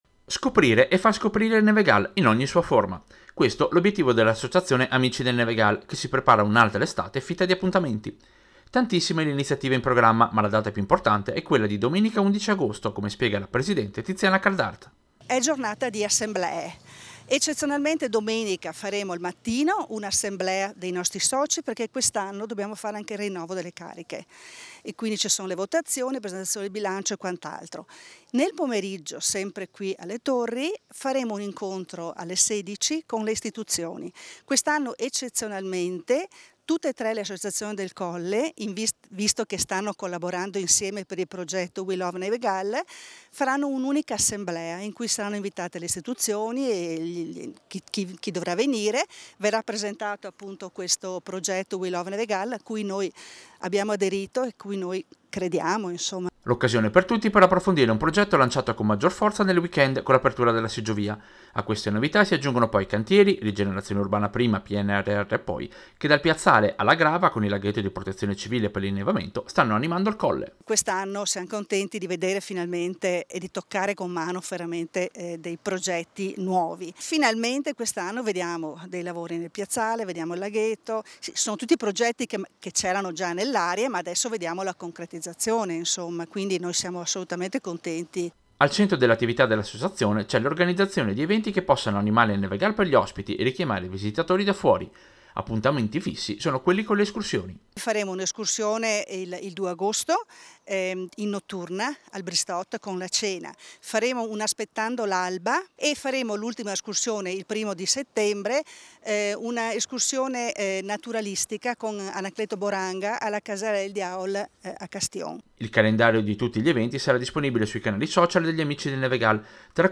Servizio-Amici-del-Nevegal-programma-e-assemblea-2024.mp3